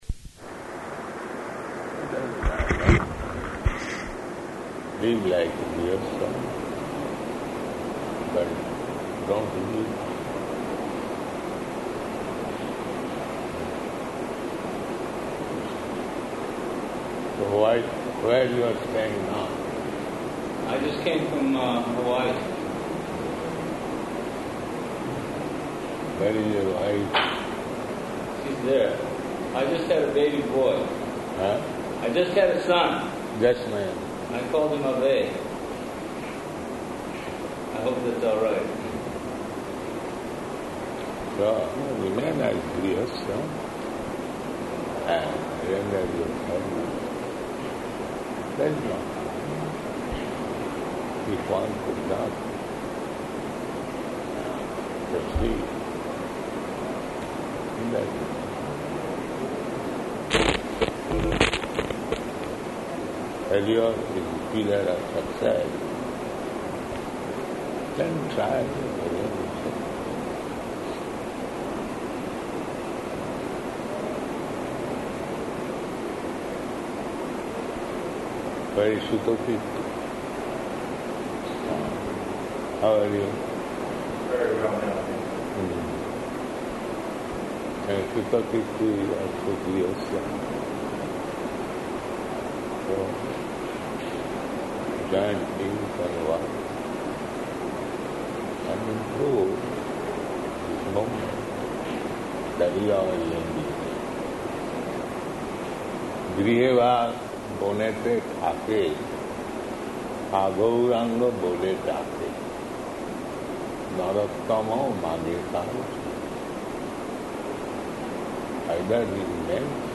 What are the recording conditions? -- Type: Conversation Dated: August 17th 1977 Location: Vṛndāvana Audio file